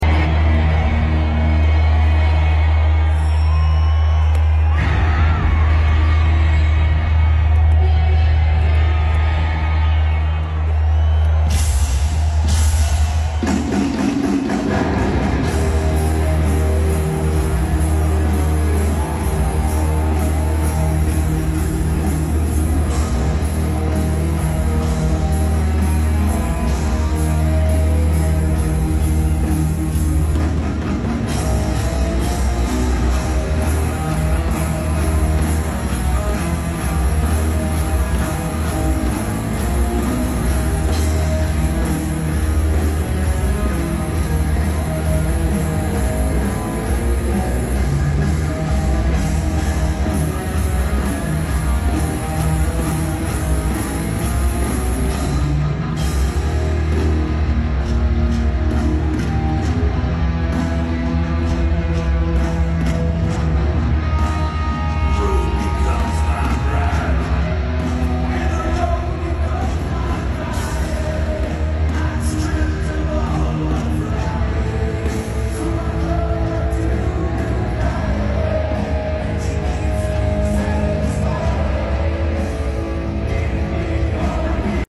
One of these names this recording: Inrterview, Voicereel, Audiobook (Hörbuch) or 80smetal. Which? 80smetal